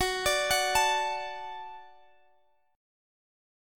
Listen to Gbm#5 strummed